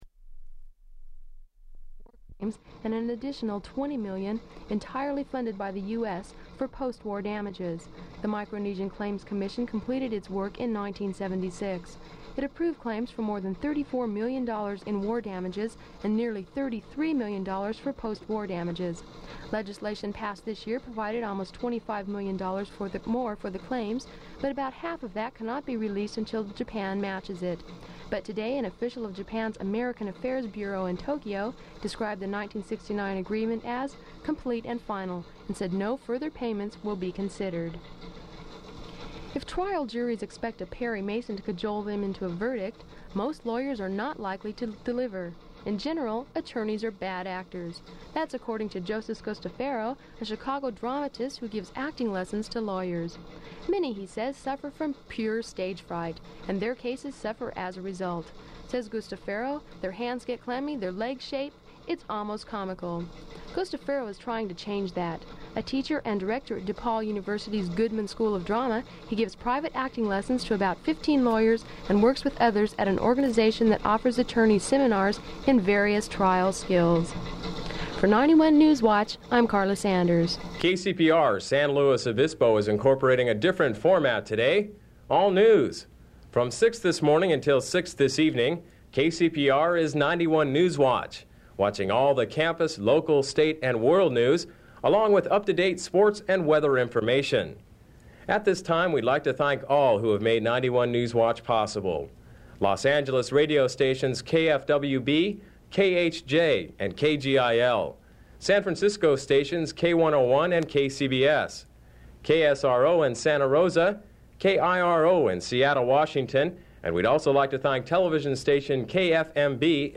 Tape damage increases, ceased recording
Form of original Open reel audiotape